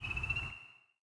frog1.wav